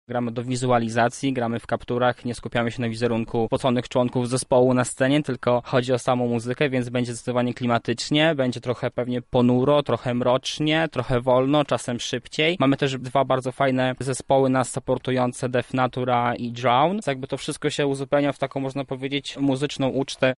Widzowie wsłuchają się w muzykę instrumentalną okraszoną tekstami ze znanego filmu.